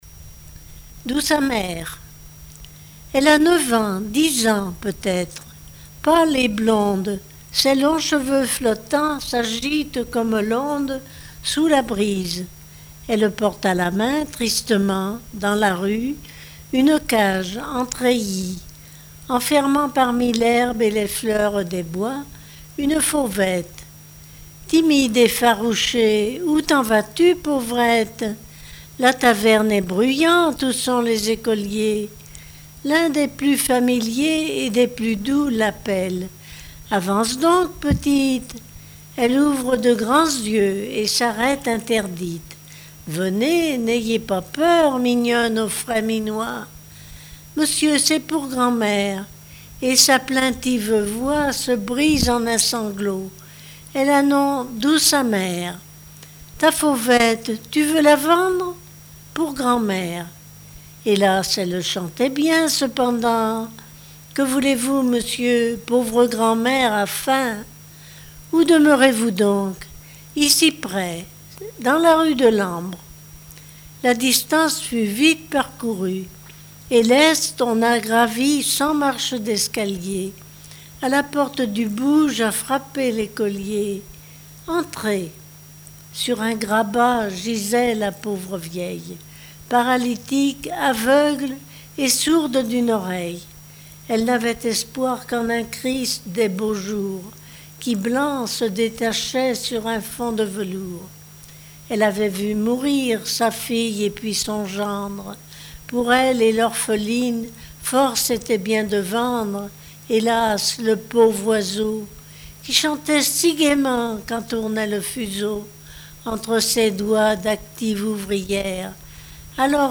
Genre conte